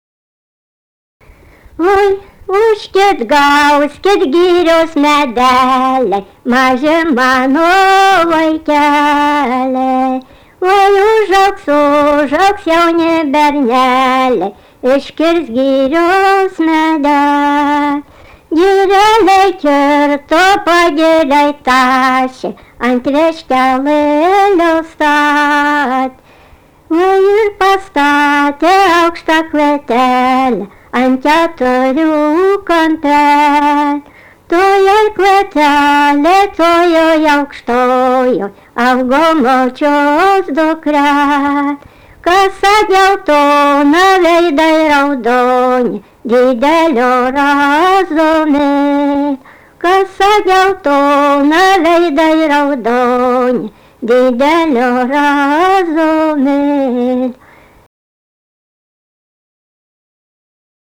daina
vokalinis